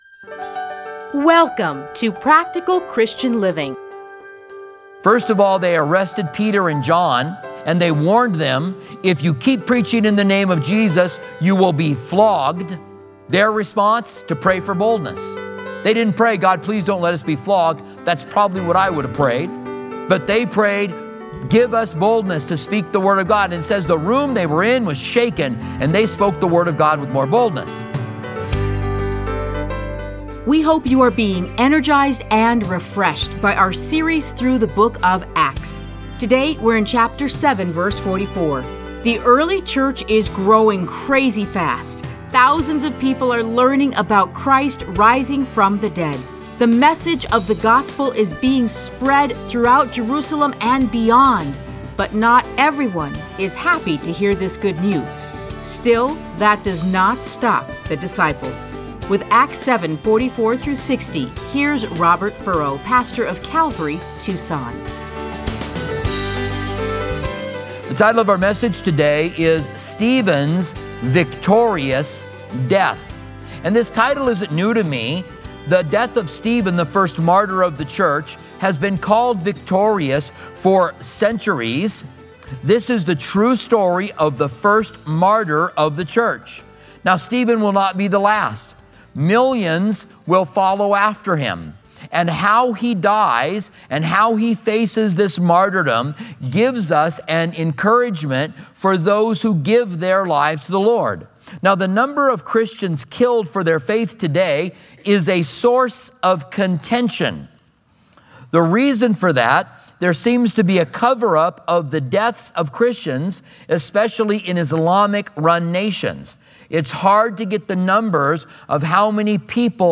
Listen to a teaching from Acts 7:44-60.